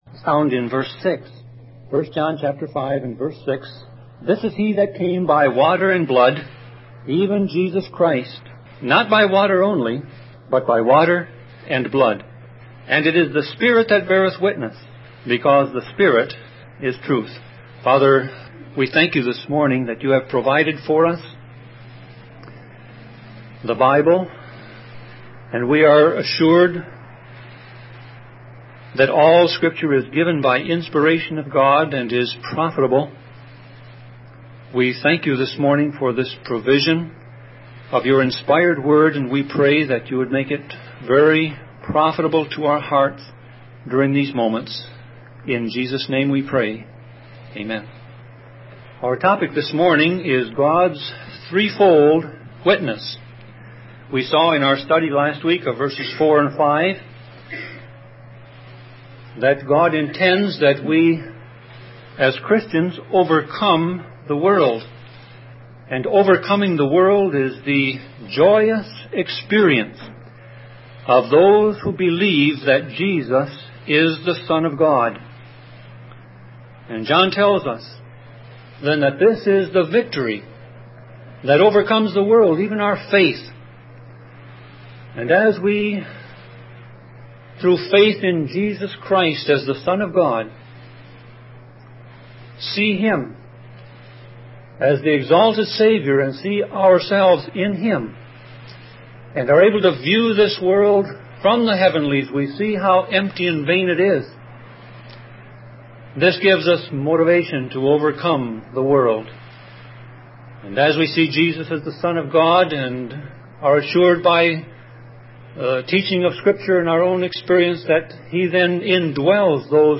Series: Sermon Audio